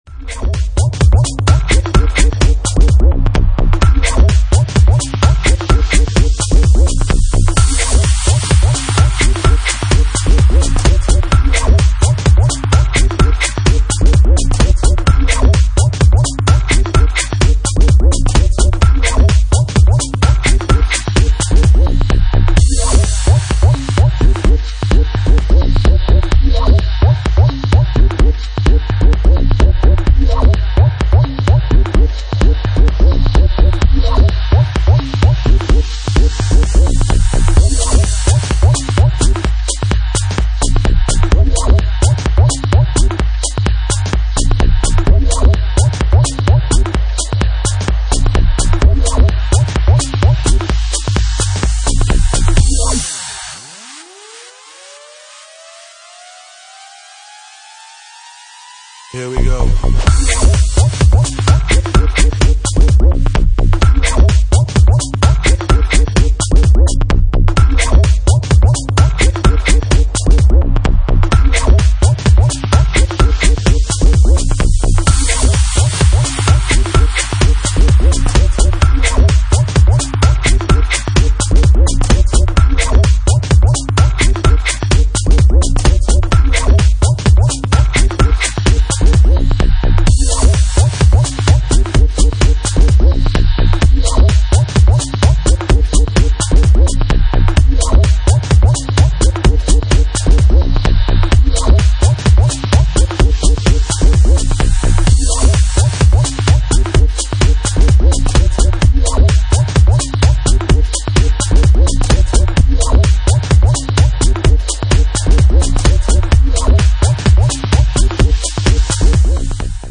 Genre:Jacking House